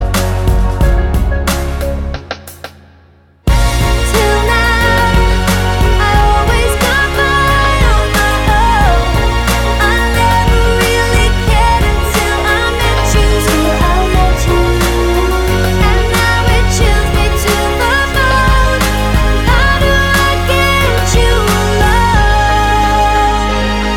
For Duet Dance 3:08 Buy £1.50